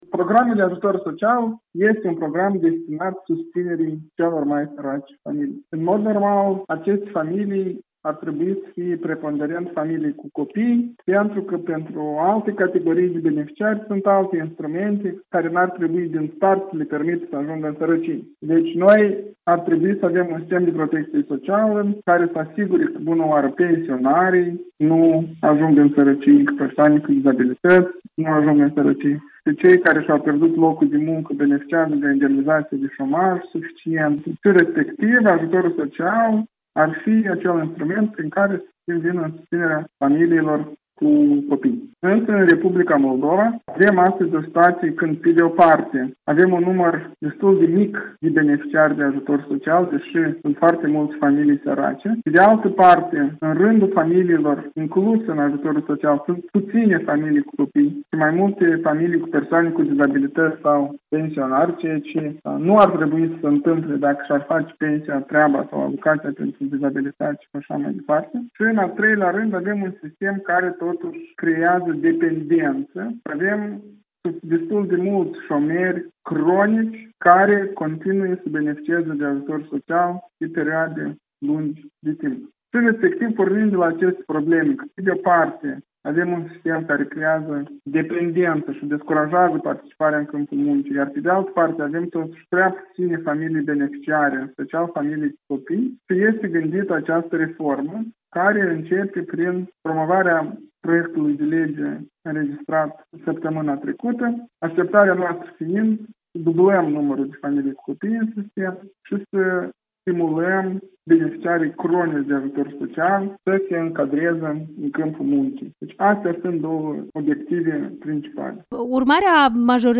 Interviu cu deputatul PAS, Dan Perciun